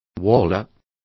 Complete with pronunciation of the translation of warlock.